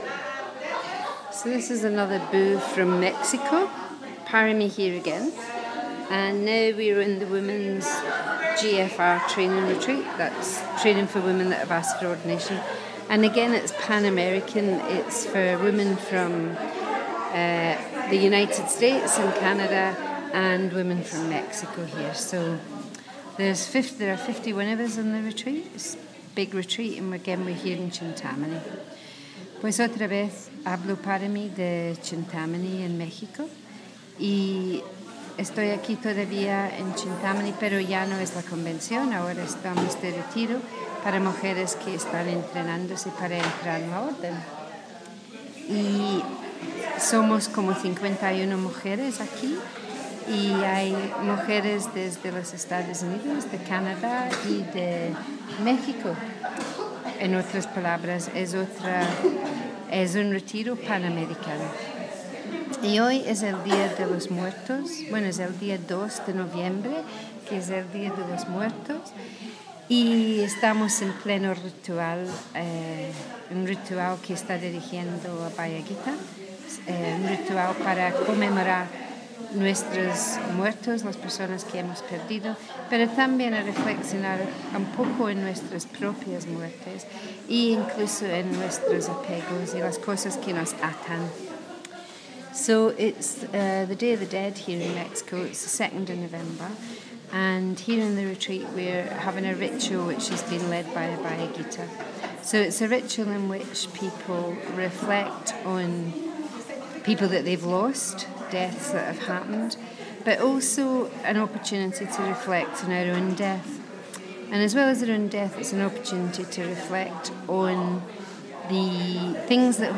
A wee report from a Pan American retreat for women in the Triratna Buddhist Community...